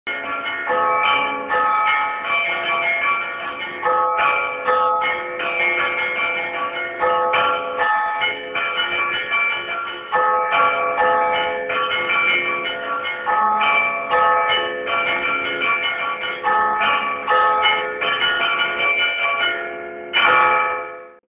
Glocken „Beiern“
Unter „Beiern“ versteht man eine festliche Art des rhytmischen Glockenläutens, wobei sehr viel Kraft, Anstrengung und Gefühl vom „Spieler“ abverlangt werden.
Die Melodien werden nach alten überlieferten Vorlagen oder nach freier Improvisation gespielt.
Aufzeichnung „Glocken- Beiern“ während der Rosenfestprozession